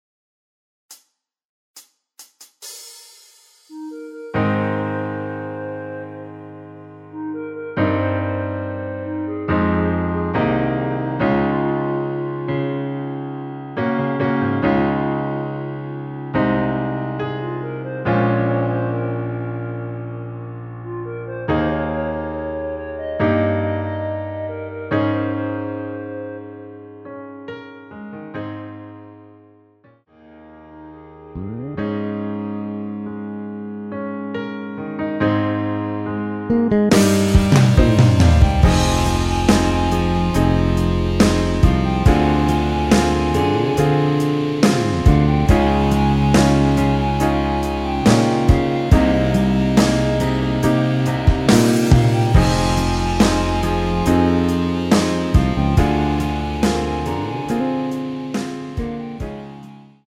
원키에서(+4)올린 멜로디 포함된 MR입니다.
전주없이 노래가 시작되는곡이라 카운트 만들어놓았습니다.
Ab
앞부분30초, 뒷부분30초씩 편집해서 올려 드리고 있습니다.